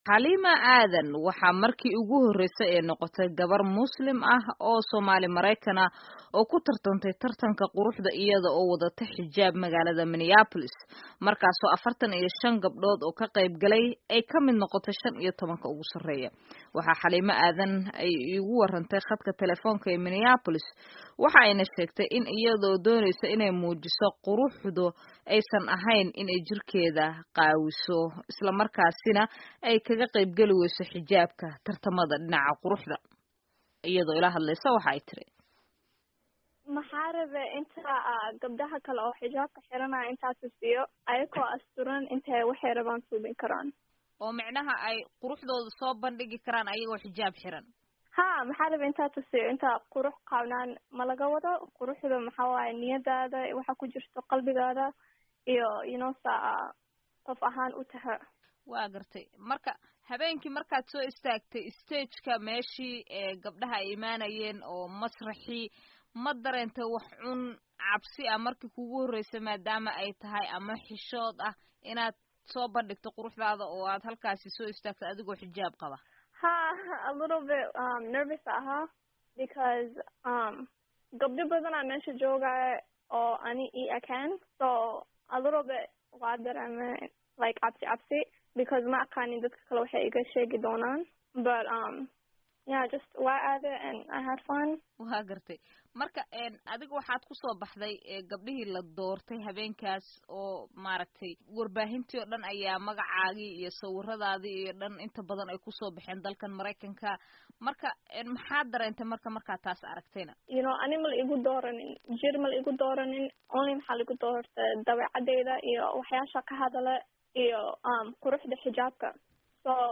Dhegayso: Waraysi ay VOAda la yeelatay Gabadhii Xijaabnayd ee Tartanka Quruxda Maraykanka ka qayb gashay